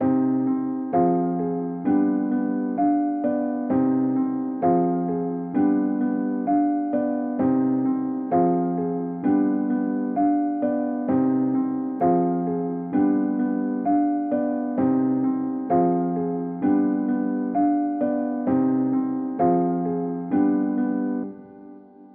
暴徒钢琴
标签： 130 bpm Trap Loops Piano Loops 3.73 MB wav Key : Unknown
声道立体声